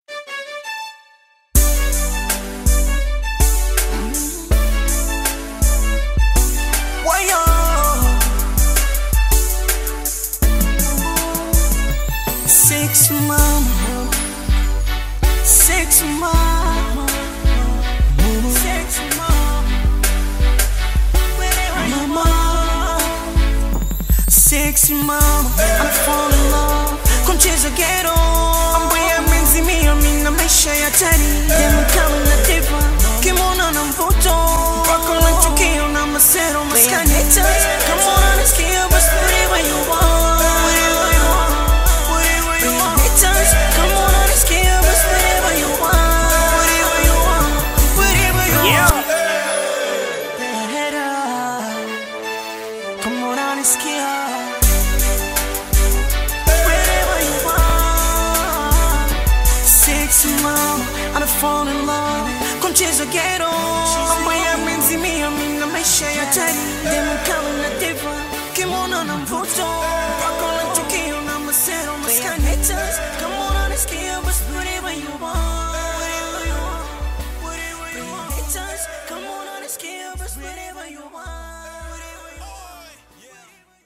classic Bongo Flava/R&B-soul single
Genre: Bongo Flava